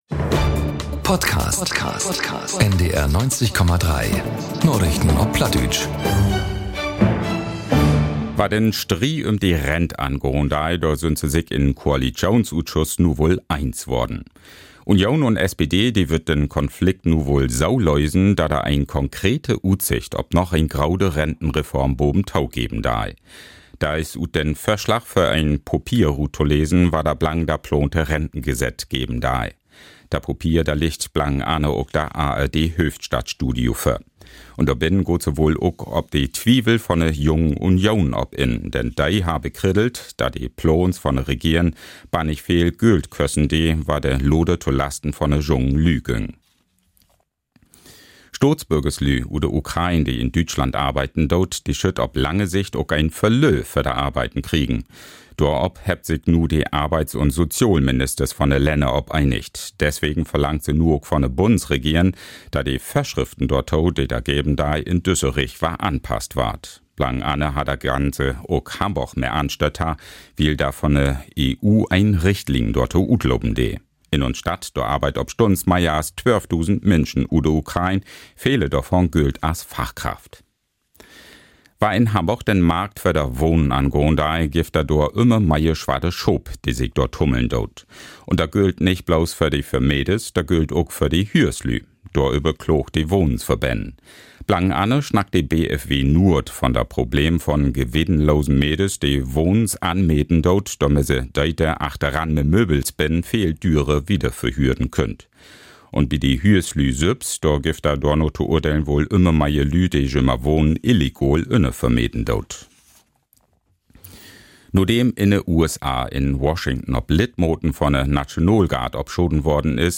Narichten op Platt 28.11.2025 ~ Narichten op Platt - Plattdeutsche Nachrichten Podcast